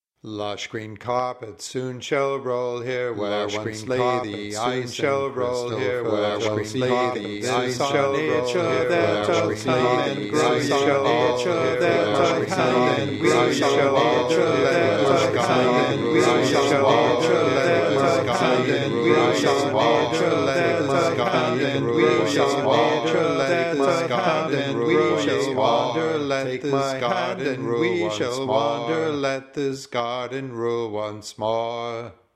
a mess without a good timbral variety of singers), and I won't even dare try 12 at this time.
Spring Round 6 part voice.mp3